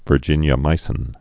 (vər-jĭnyə-mīsĭn)